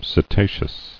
[se·ta·ceous]